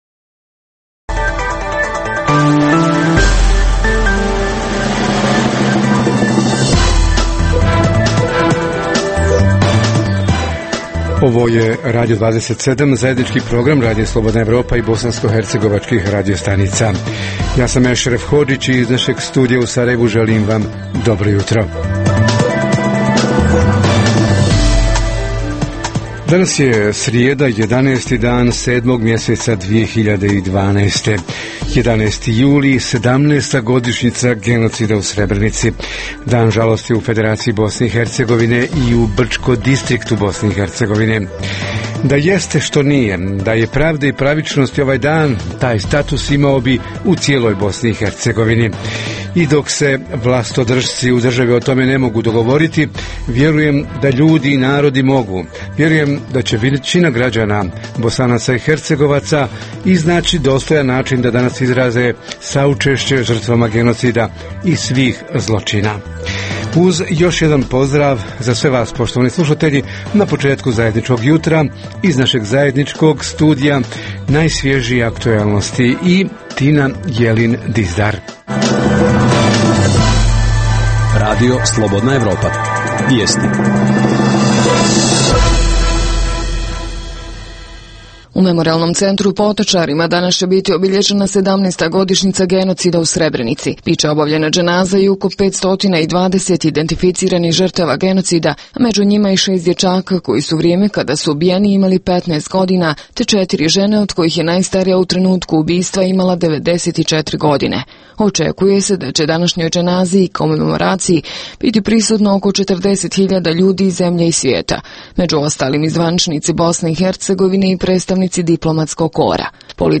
Jednostavno, šta mladi ovog ljeta rade, a šta bi mogli i trebali da rade? a Reporteri iz cijele BiH javljaju o najaktuelnijim događajima u njihovim sredinama.